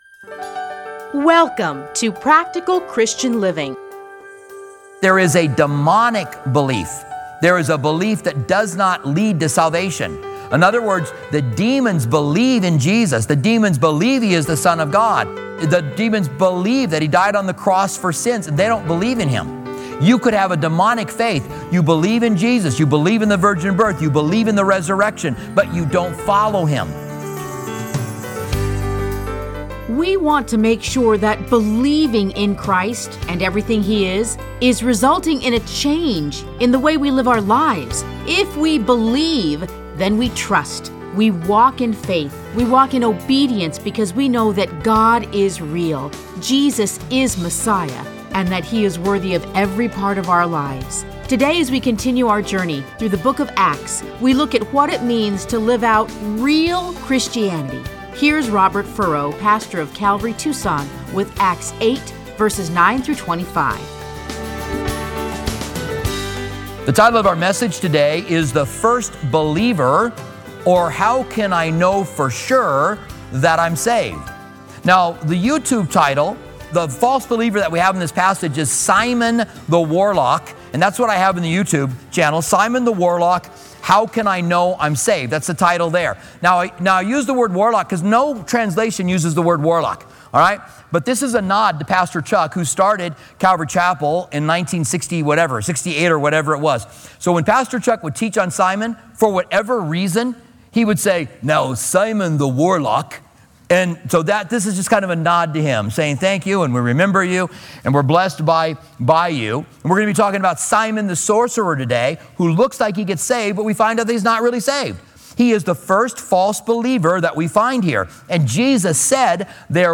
Listen to a teaching from Acts 8:9-25.